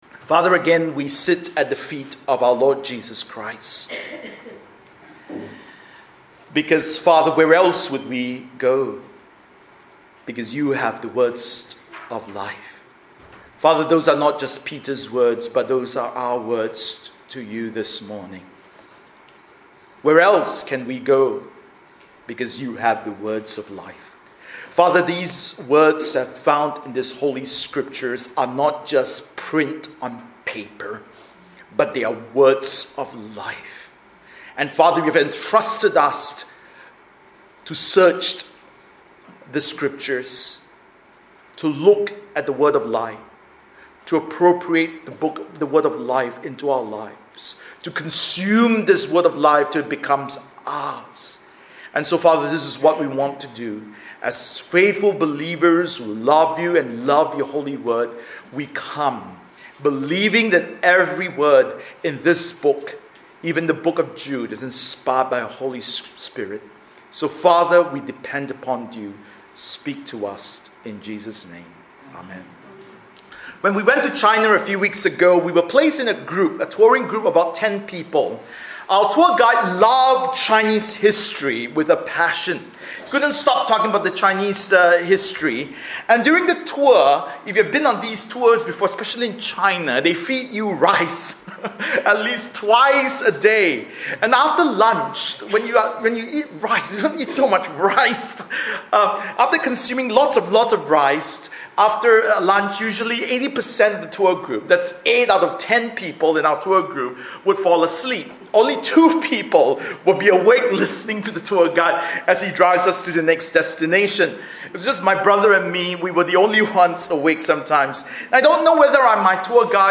Bible Text: Jude 1-4 | Preacher